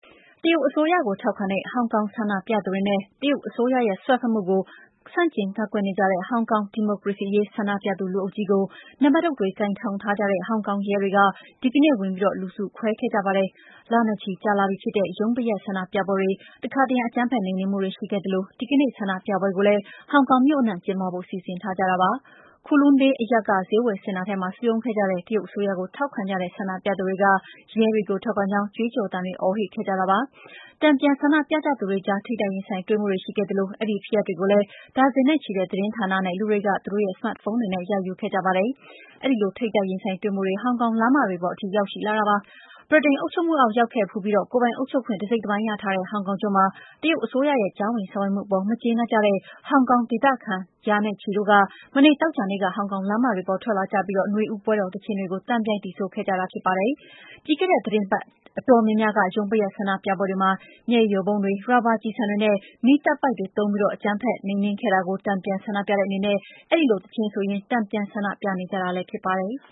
Kowloon Bay အရပ်က ဈေးဝယ်စင်တာထဲမှာ စုရုံးခဲ့ကြတဲ့ တရုတ်အစိုးရကို ထောက်ခံတဲ့ ဆန္ဒပြသူတွေက ရဲတွေကို ထောက်ခံကြောင်း ကြွေးကြော်သံတွေ အော်ဟစ်ခဲ့ကြပါတယ်။